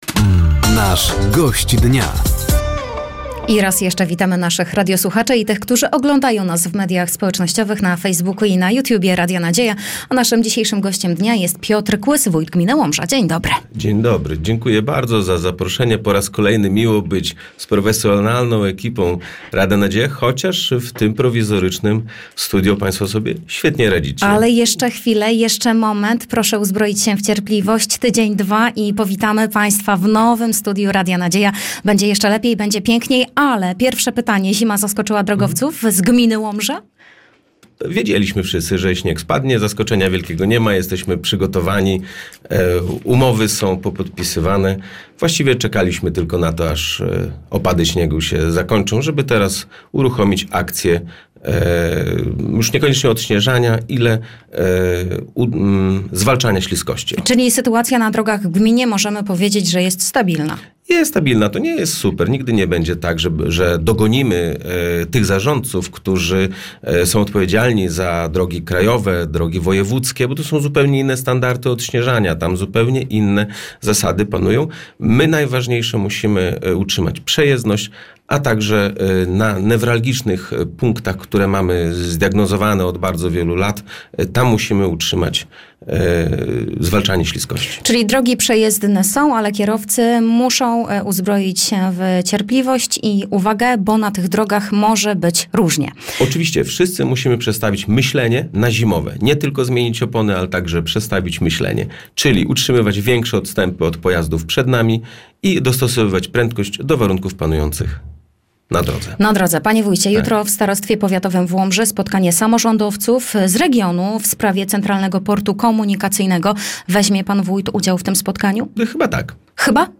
Zapraszamy do wysłuchania rozmowy z Piotrem Kłysem, wójtem gminy Łomża.